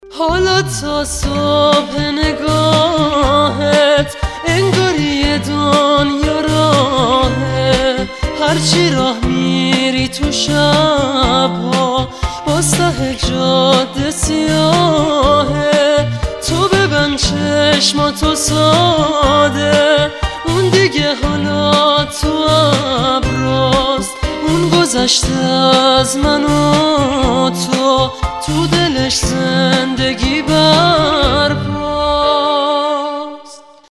زنگ موبایل باکلام نیمه غمگین